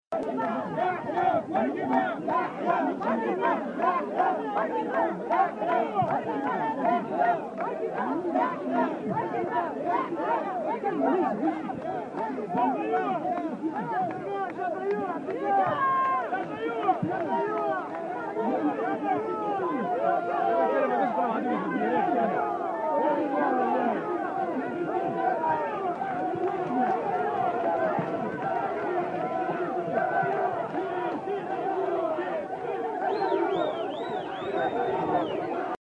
أصوات المحتجين مباشرة من شارع الحبيب بورقيبة بالعاصمة